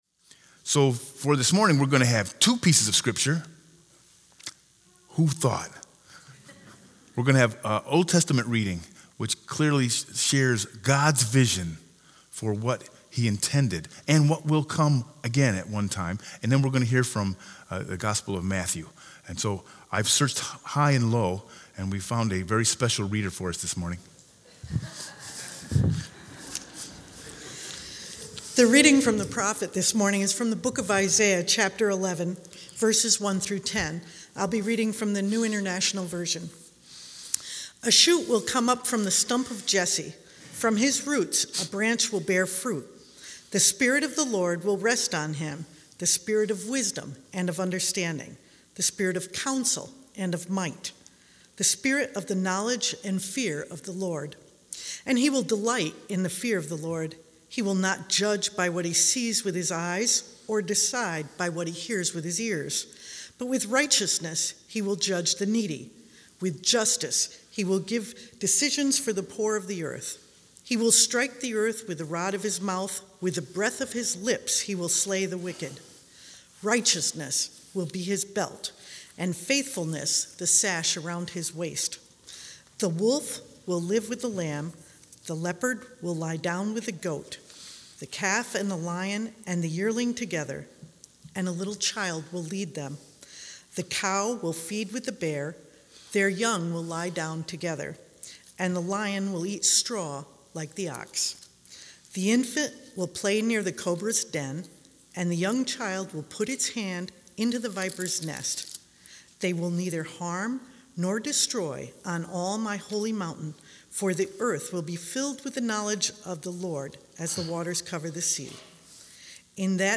Sermon 12-8-19 with Scripture Lesson Matthew 3_1-12